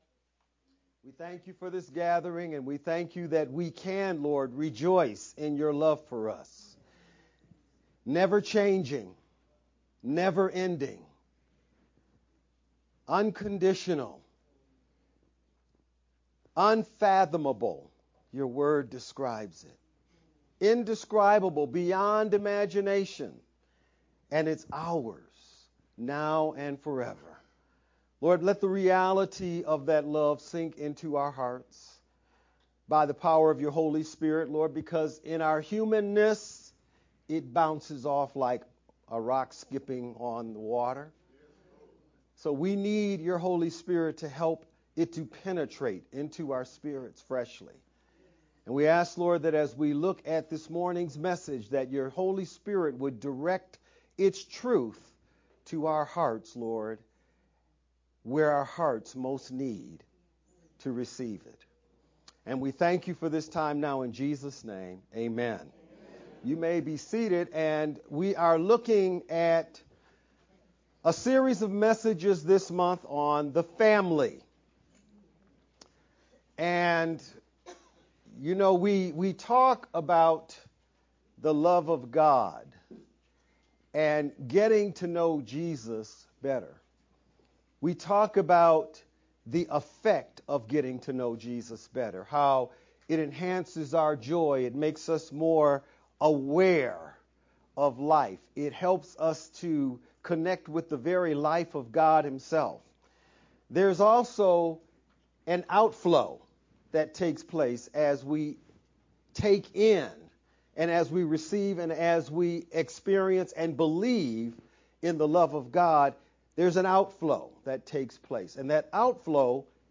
VBCC-Sermon-only-June-1st_Converted-CD.mp3